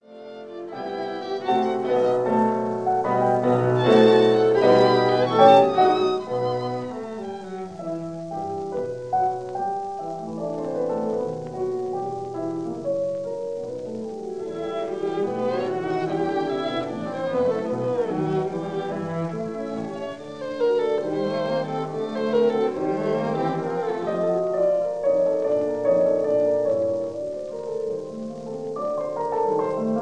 violin
cello